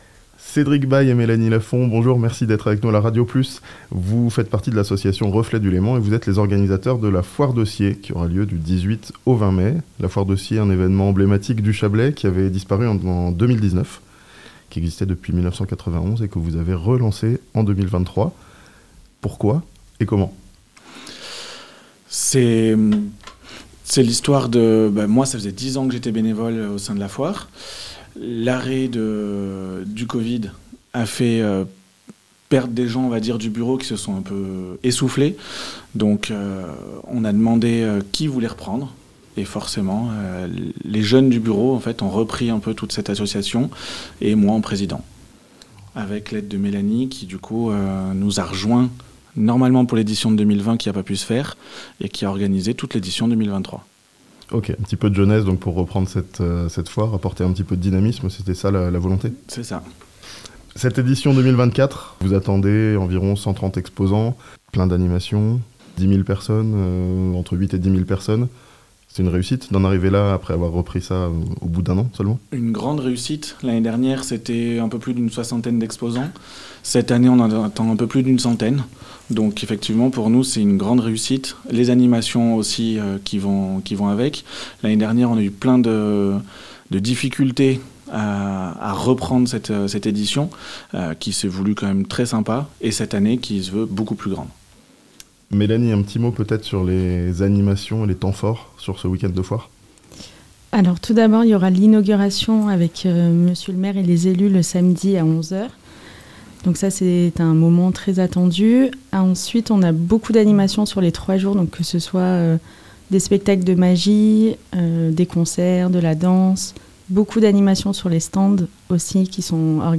Evènement emblématique du Chablais, la foire de Sciez aura lieu du 18 au 20 mai (interview)